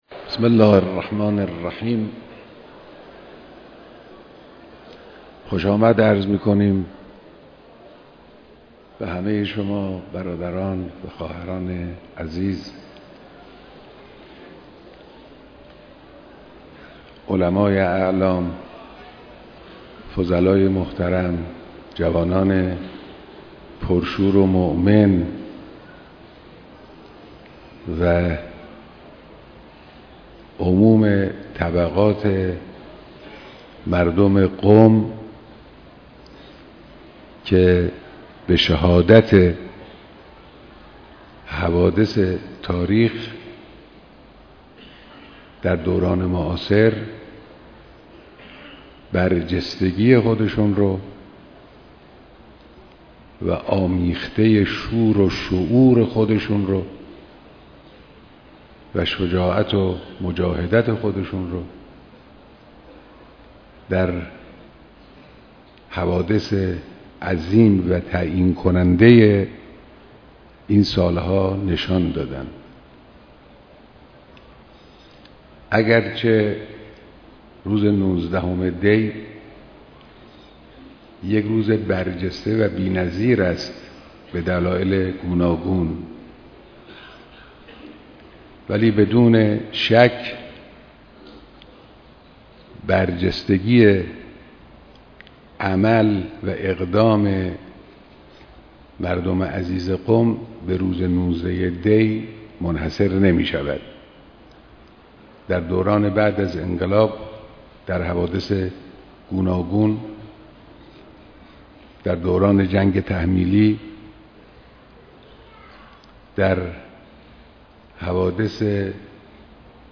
بيانات در ديدار مردم قم